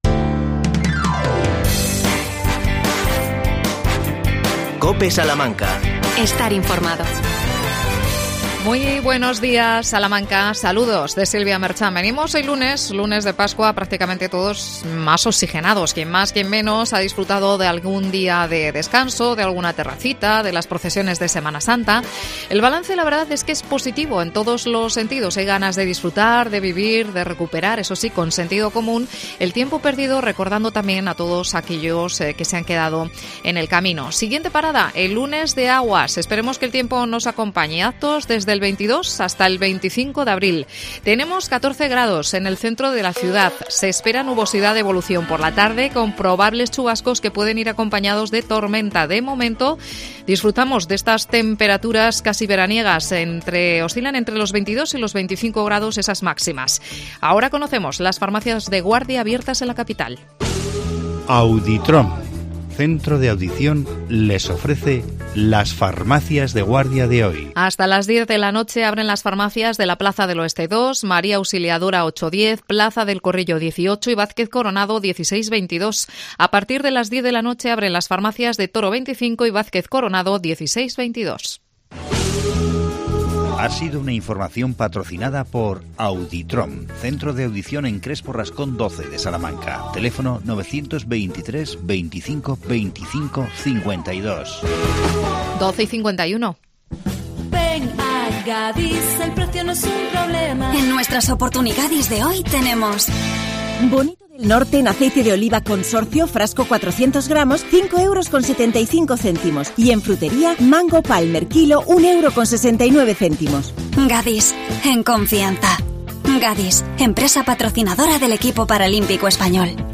AUDIO: Día del Pendón en La Alberca. Entrevistamos al alcalde Miguel Ángel Luengo.